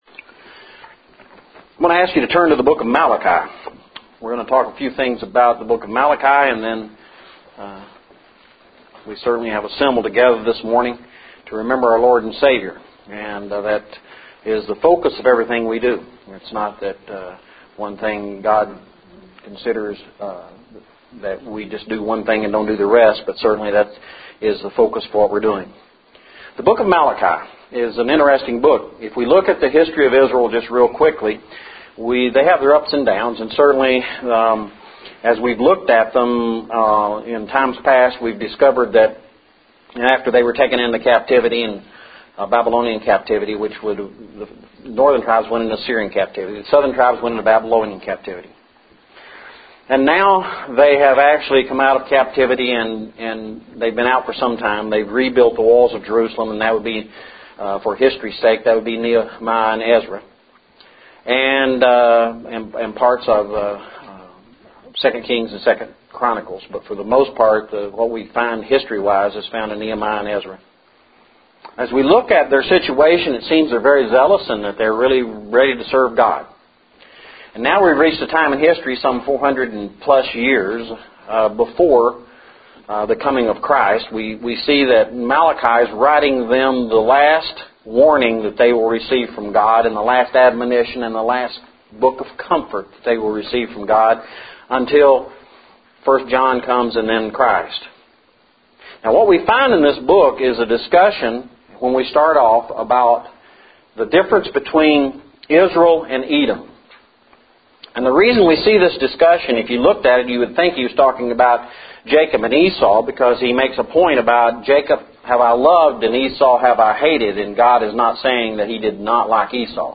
Resurrection of Jesus Lesson – 12/12/10 – Waynesville Church of Christ
Resurrection of Jesus Lesson – 12/12/10